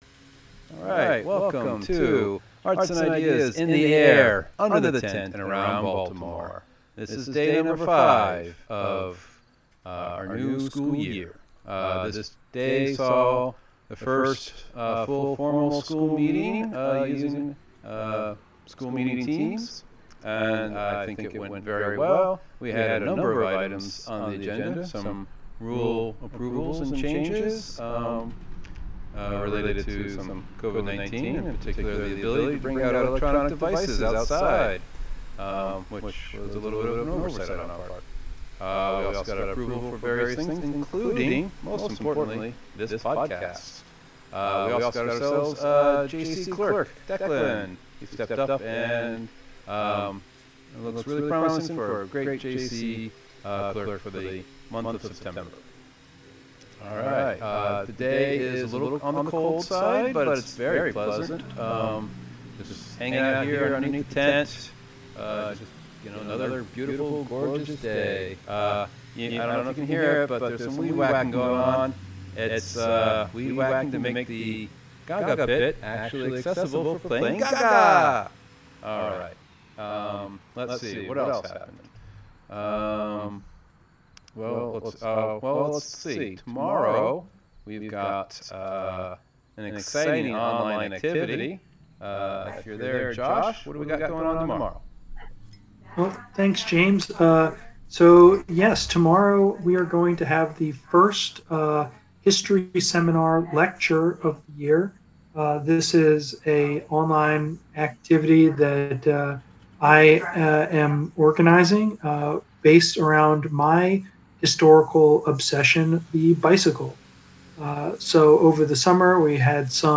Alternative recording with Discord audio feedback issues being tested.